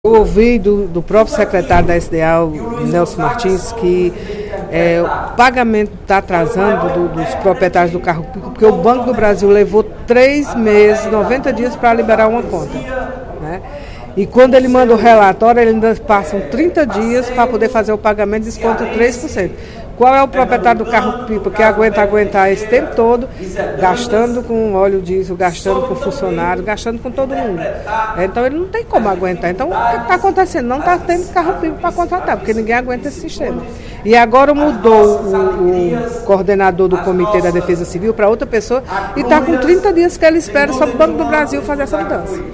A deputada Mirian Sobreira (PSB) informou, no primeiro expediente da sessão plenária desta quinta-feira (13/12), que irá apresentar um requerimento cobrando do Banco do Brasil explicações sobre o porquê da demora de 90 dias para abrir as contas destinadas ao depósito dos pagamentos dos caminhões pipa.